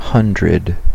Ääntäminen
Synonyymit centurie centime centaine sou cenne centin cenne noire eurocent eurocentime Ääntäminen France (Paris): IPA: [sɑ̃] Tuntematon aksentti: IPA: /sɑ̃(t)/ IPA: /sɛnt/ IPA: /sɛn/ (avec sigmatisme latéral): IPA: [ɬ͡sɛnt]